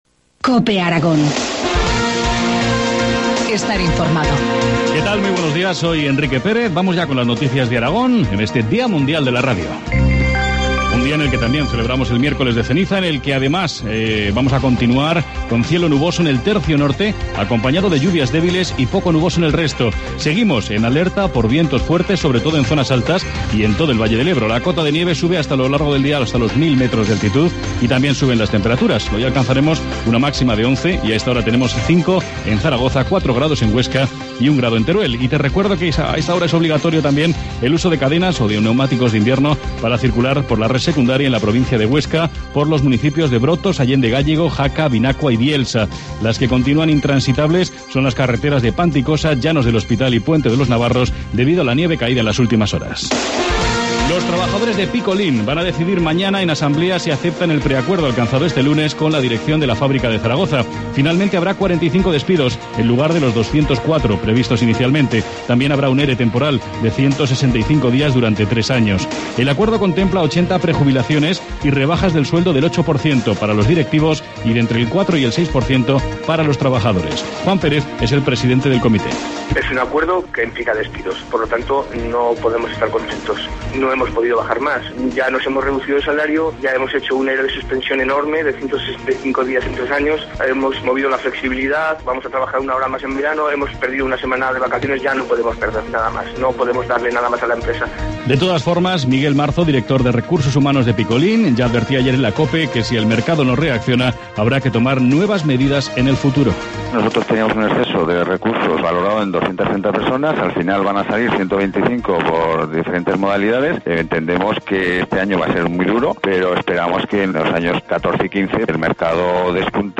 Informativo matinal, miércoles 13 de febrero, 8.25 horas